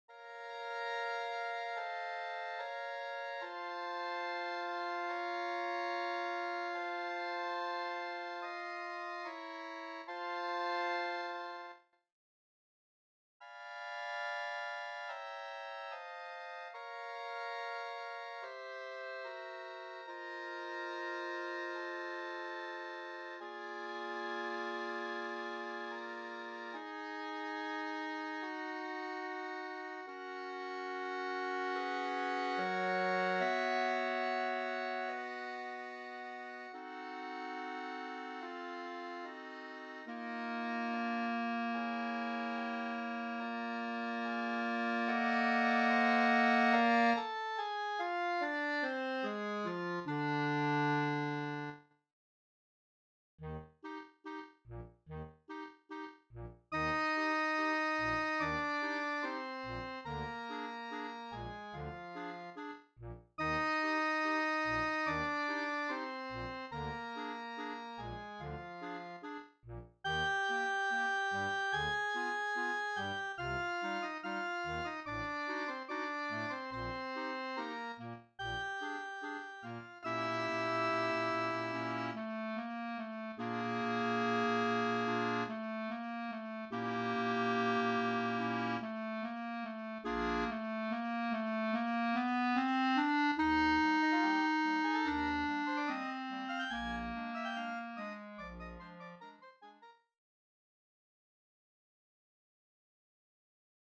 FOR CLARINET CHOIR